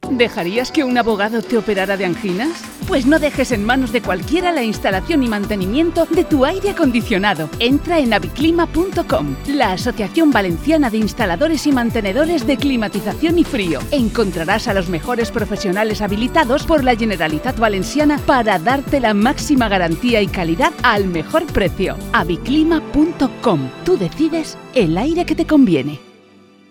Accede a la Cuña de la Campaña,